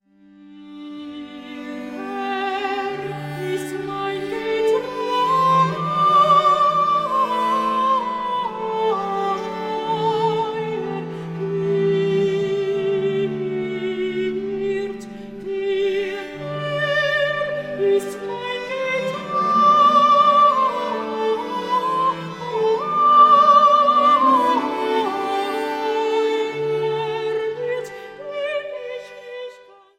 Motette für zwei vierstimmige Chöre